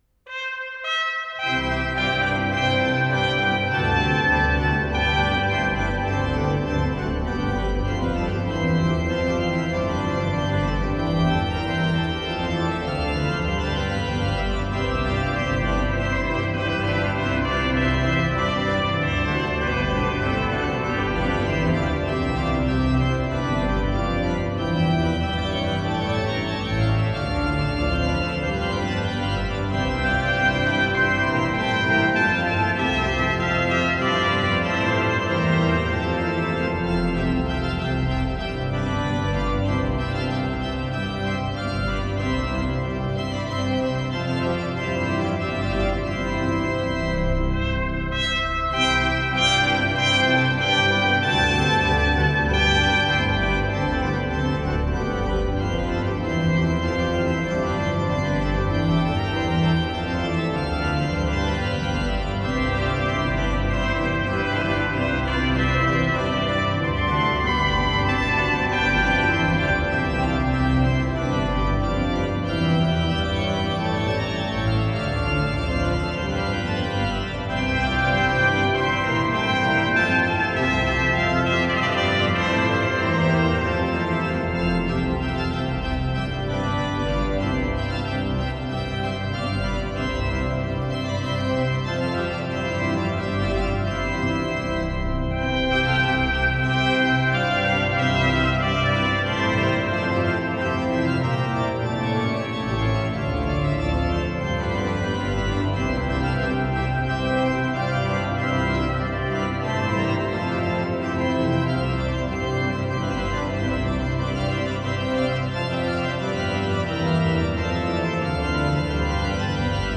ORGANO y TROMPETA
Las más bellas obras para Trompeta y Órgano
grabadas en la Catedral Metropolitana de Valladolid y en otros lugares
Coral